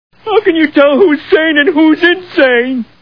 The Simpsons [Homer] Cartoon TV Show Sound Bites